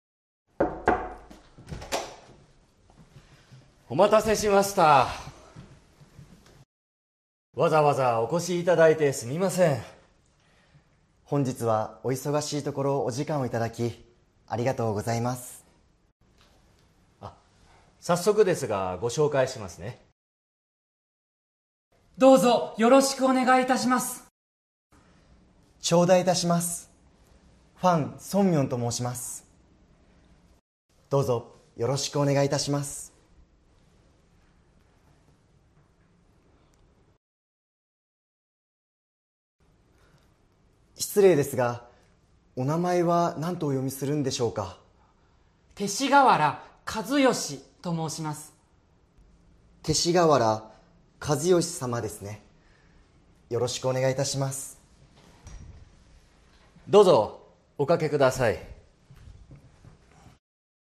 Role-play Setup
Your supervisor is introducing you to two clients from another company.
Conversation Transcript